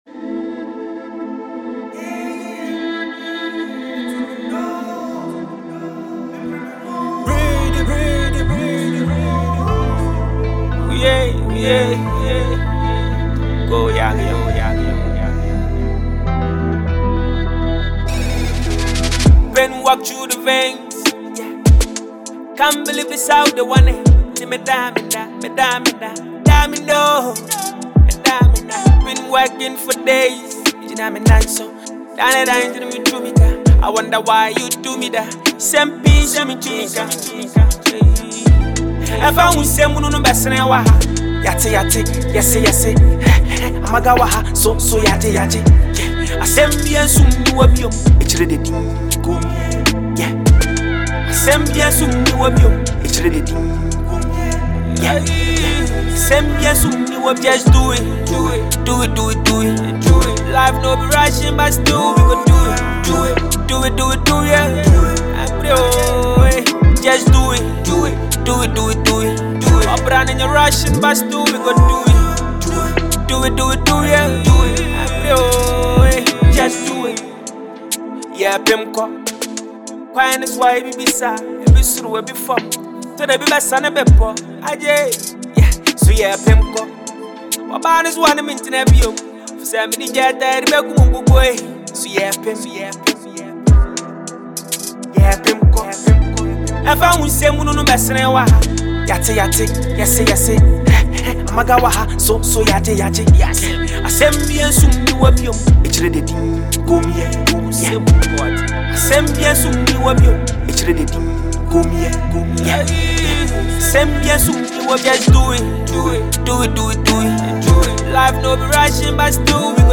motivational anthem